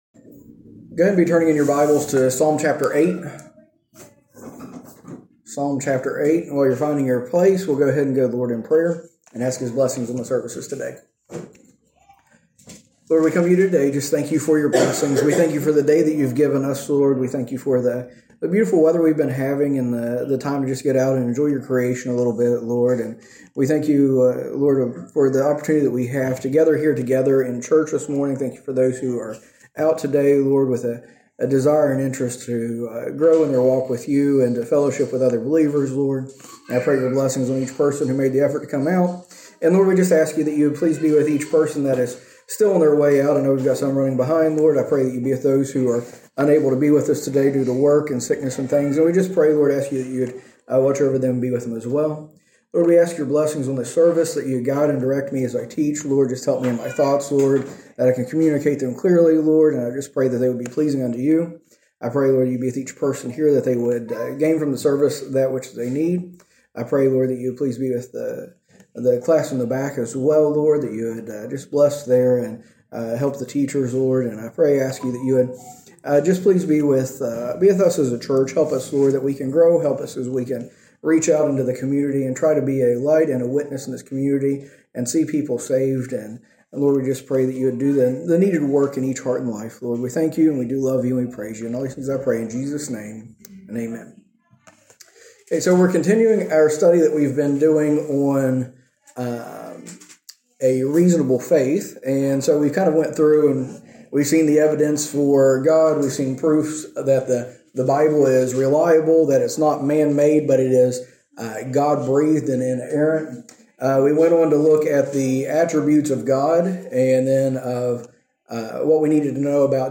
A message from the series "A Reasonable Faith."